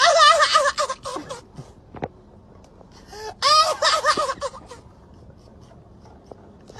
Suara Ngakak Laugh Annoying
Kategori: Suara ketawa
suara-ngakak-laugh-annoying-id-www_tiengdong_com.mp3